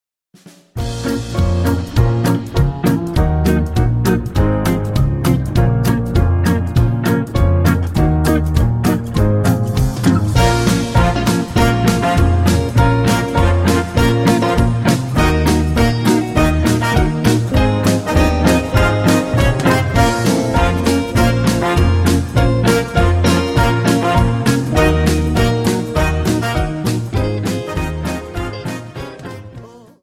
Dance: Quickstep